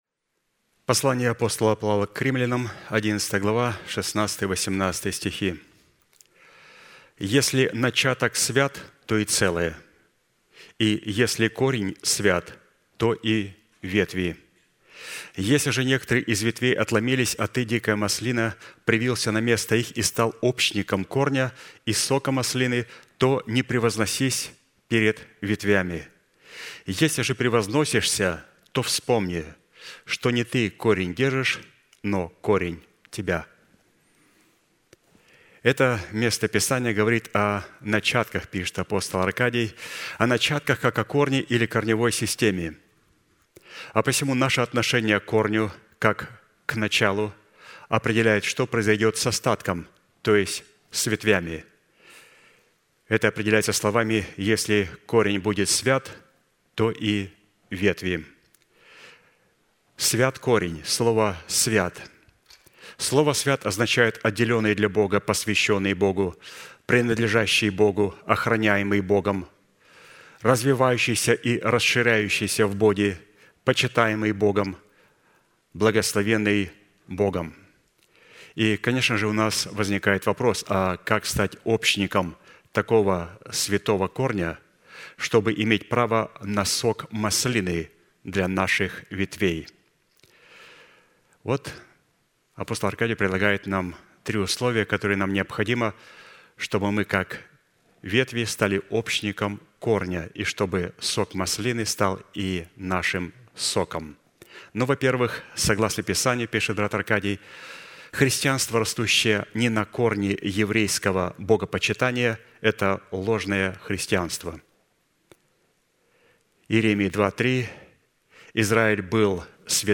Служение: Воскресенье